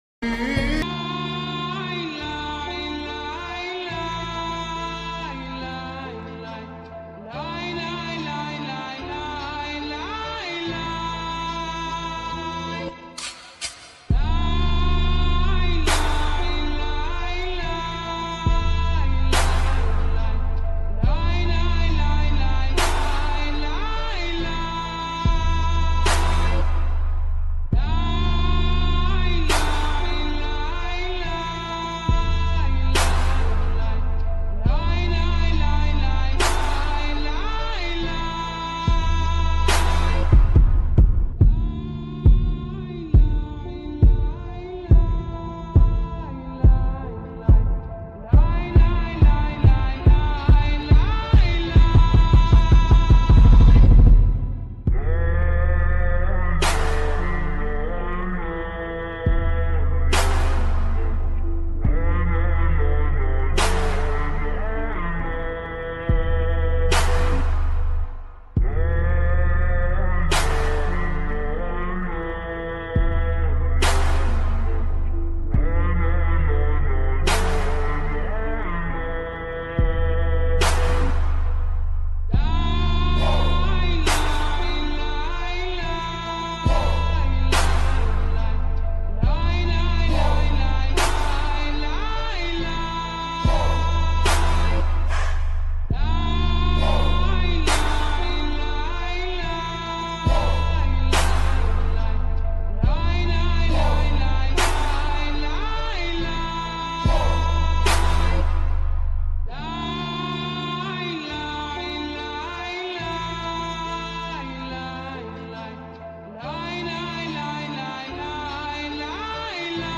Slow Reverb Full Music